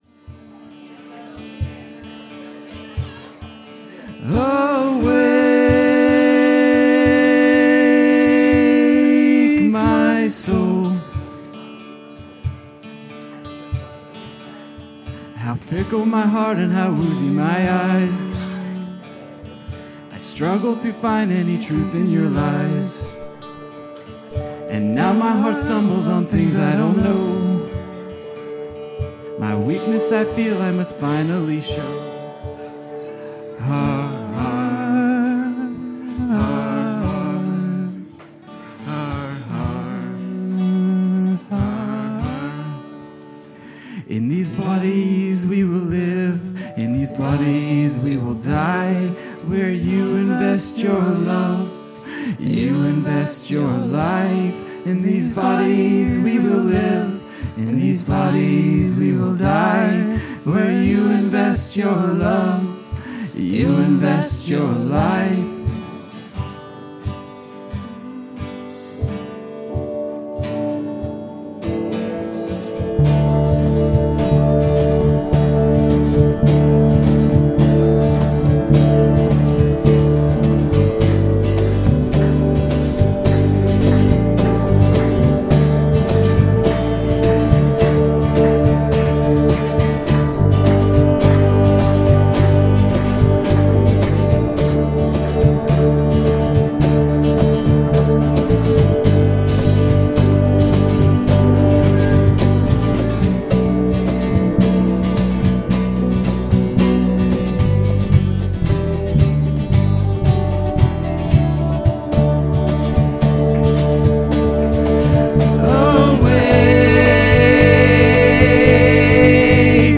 Worship 2025-06-01